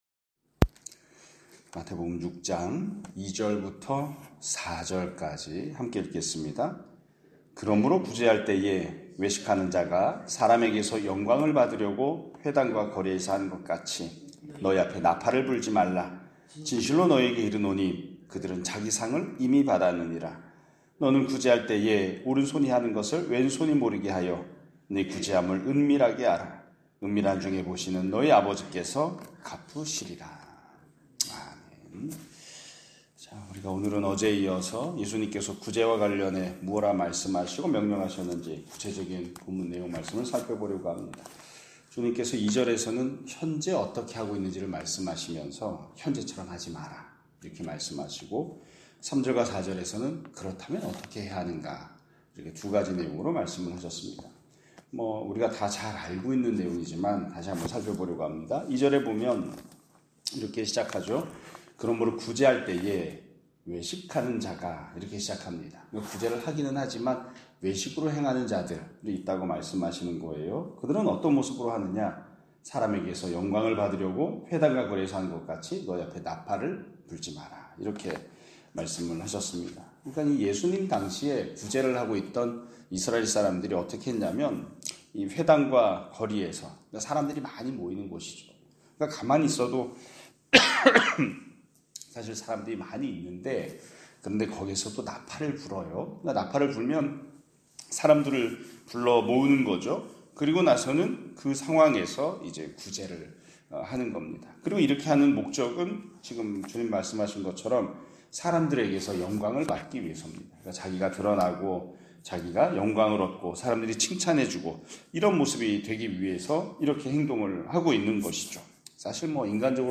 2025년 6월 11일(수요일) <아침예배> 설교입니다.